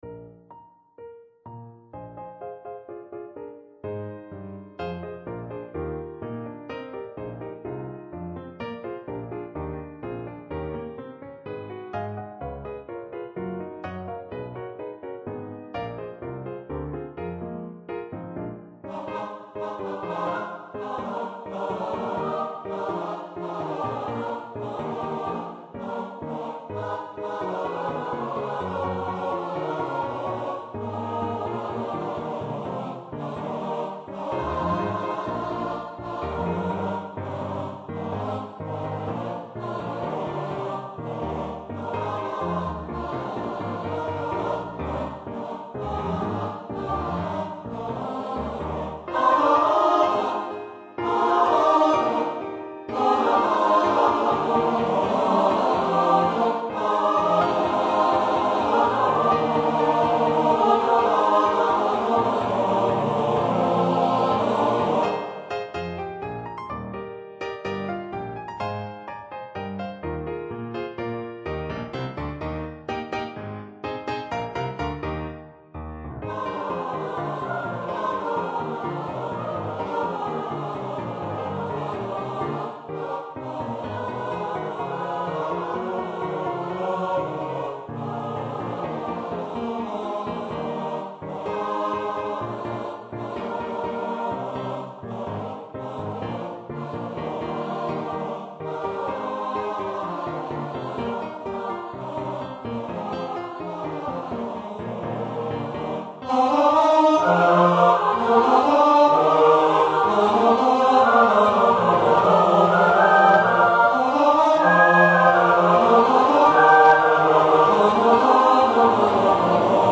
for mixed voice choir